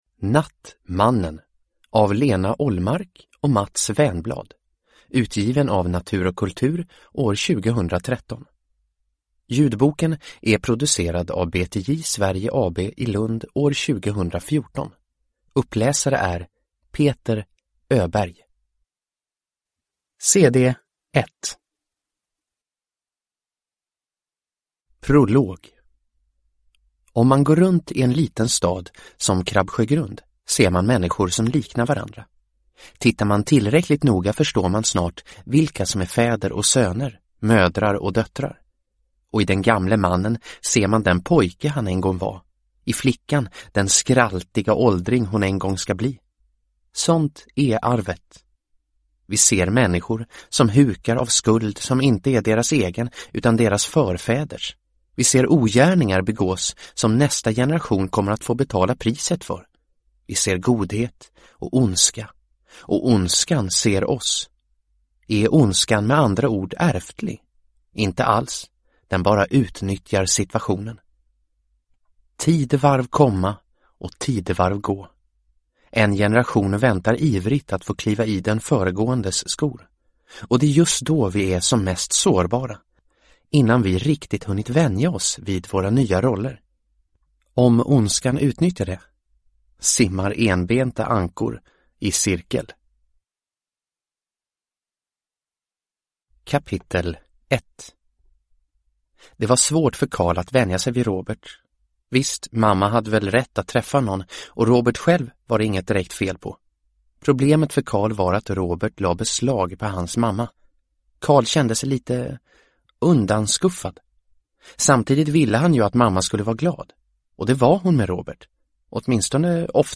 Nattmannen – Ljudbok – Laddas ner